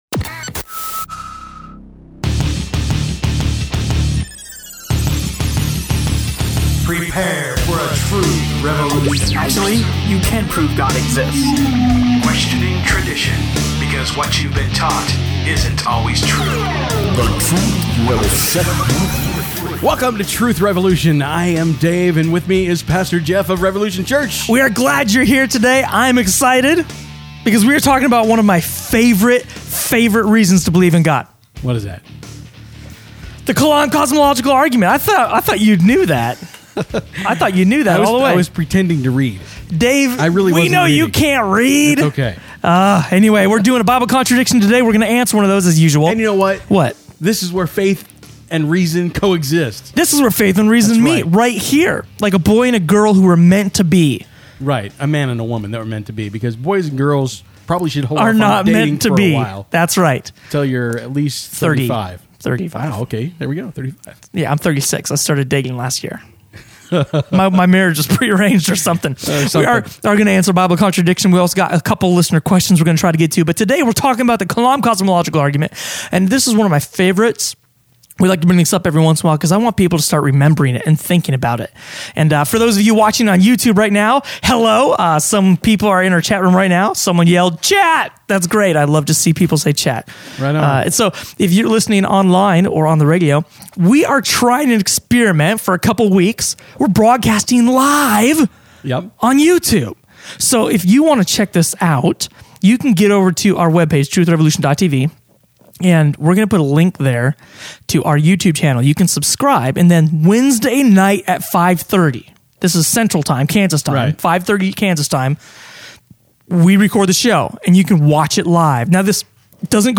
Introducing the Kalam Cosmological Argument We are broadcasting on YouTube… LIVE!